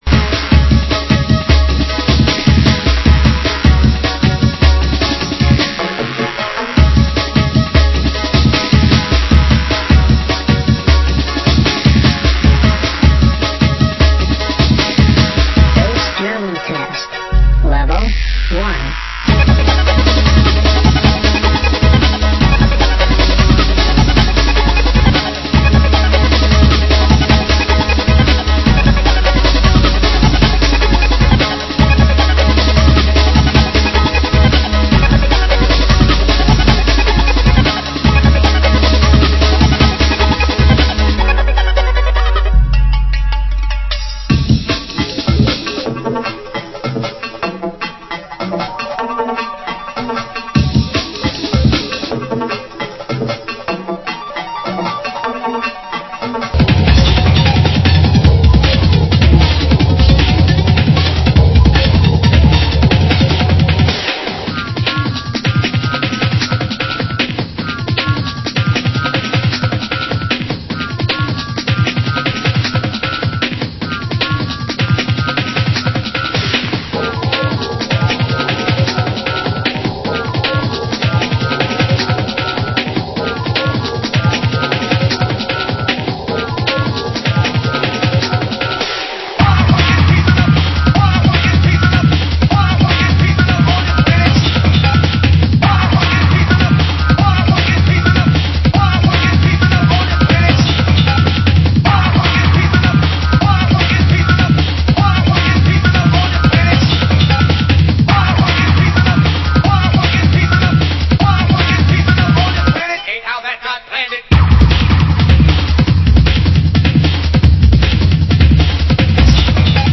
Genre: Jungle